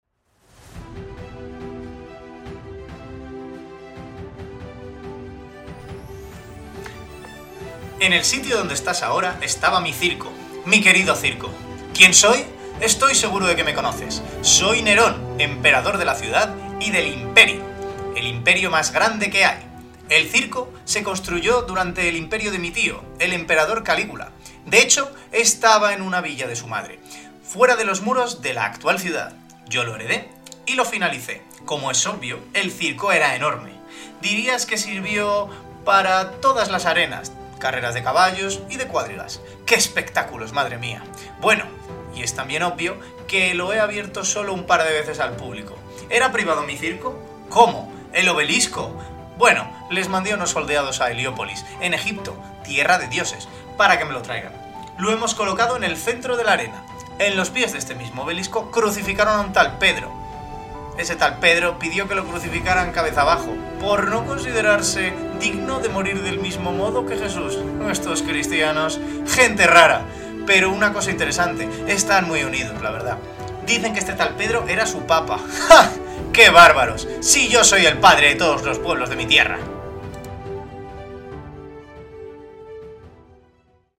Emperador Nerón habla de su circo